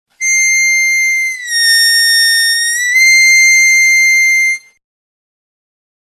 На 8-ом, 9-ом и 10-ом отверстиях понижать ноту до самого дна, возвращаясь затем к чистой ноте.